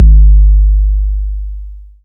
TR 808 Kick 03.wav